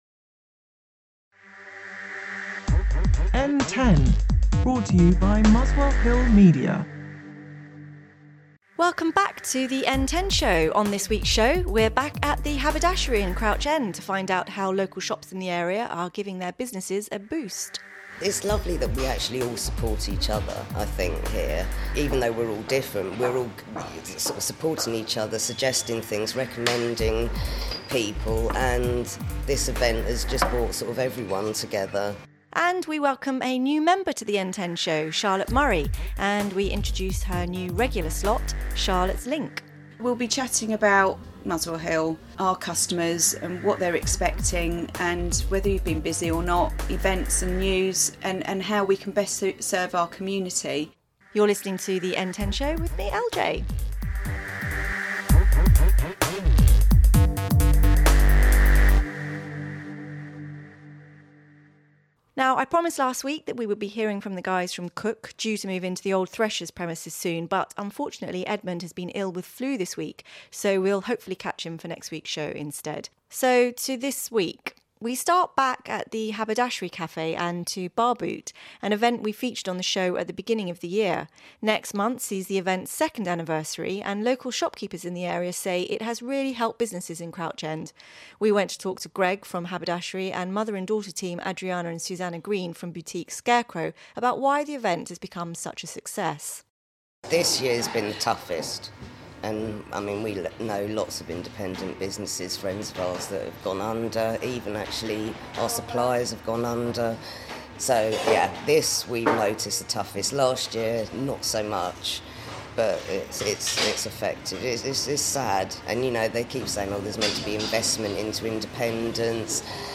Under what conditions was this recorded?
Boosting local business: We’re back at the Haberdashery in Crouch End to find out how local shops in the area are giving their businesses a boost.